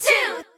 twogirls.ogg